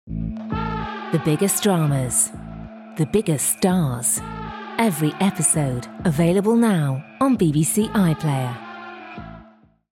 30's Northern/Scouse/Spanish,
Believable/Familiar/Soothing
• Promo